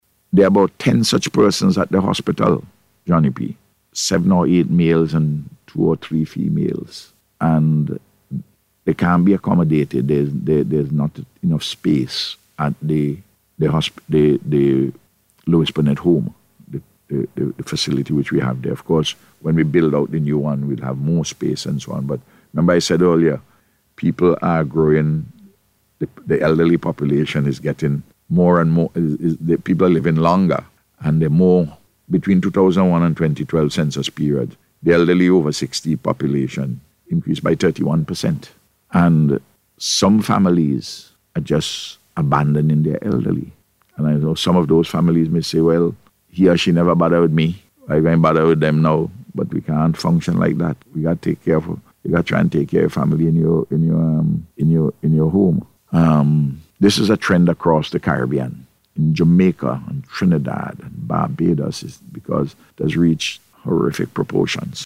Speaking on NBC’s Face to Face programme recently, the Prime Minister disclosed that persons have been leaving their elderly relatives at the Milton Cato Memorial Hospital.